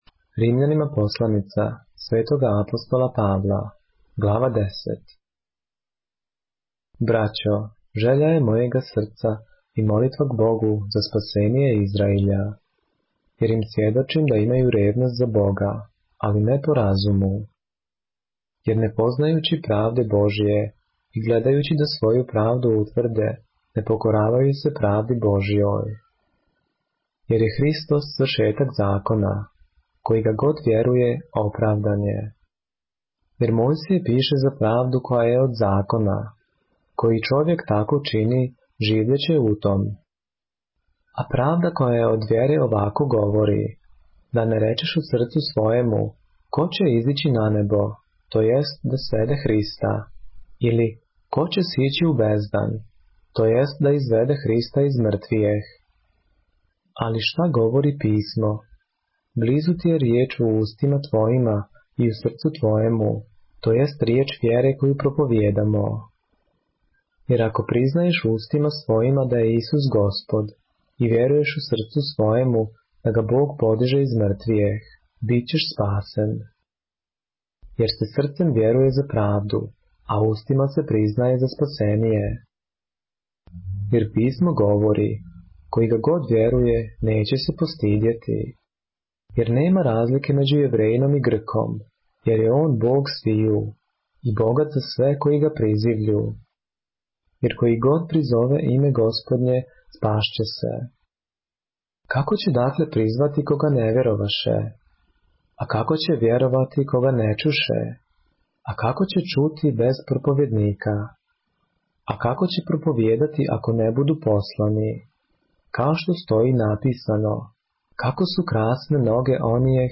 поглавље српске Библије - са аудио нарације - Romans, chapter 10 of the Holy Bible in the Serbian language